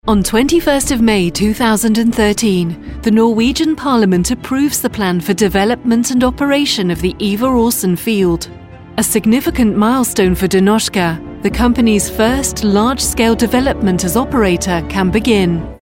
Corporate